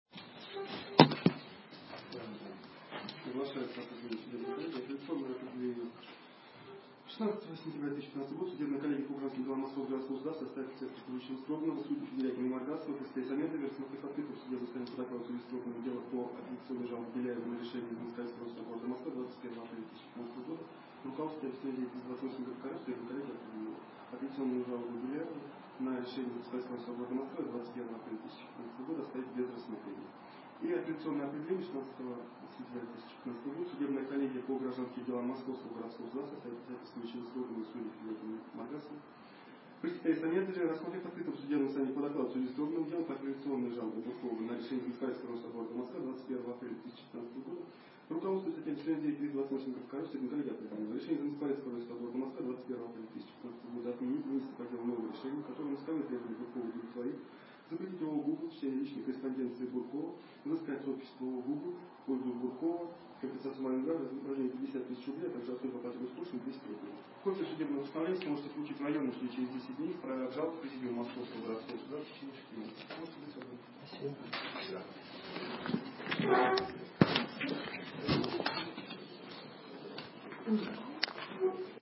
Оглашение определения Мосгорсуда против ООО "Гугл"